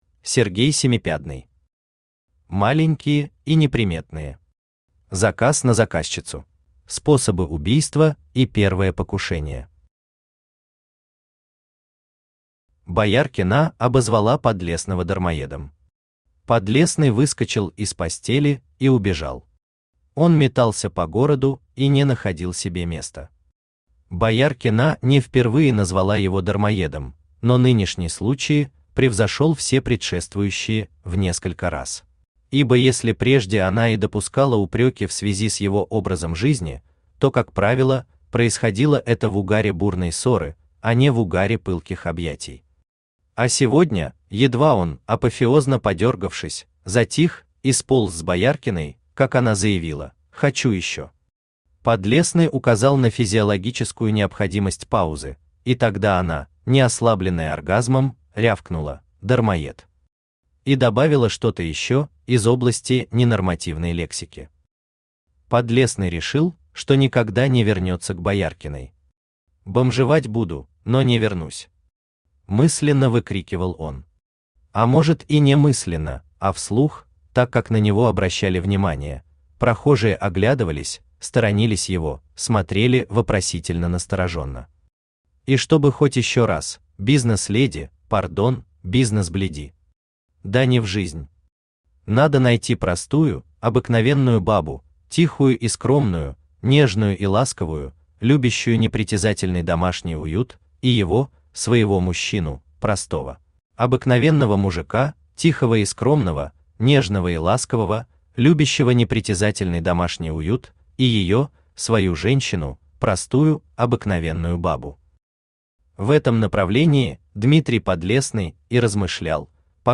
Аудиокнига Маленькие и неприметные. Заказ на заказчицу | Библиотека аудиокниг
Заказ на заказчицу Автор Сергей Семипядный Читает аудиокнигу Авточтец ЛитРес.